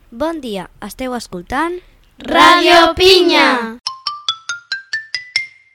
Identificació de la ràdio